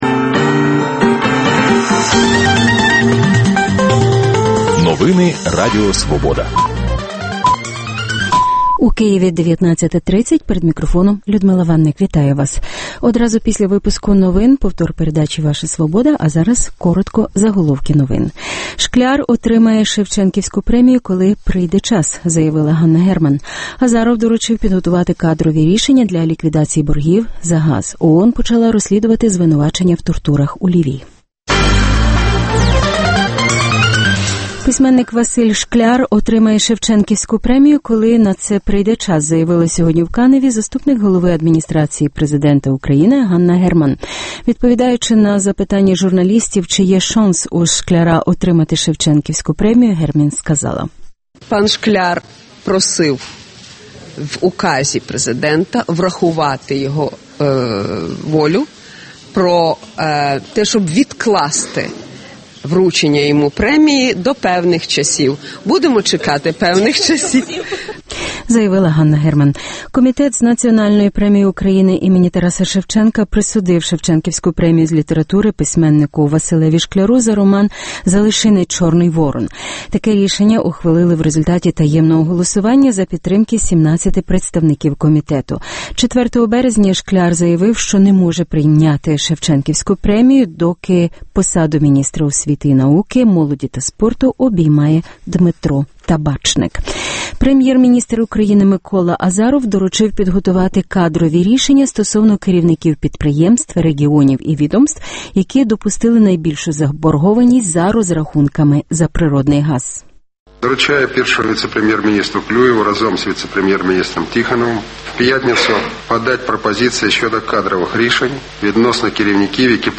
Дискусія про головну подію дня.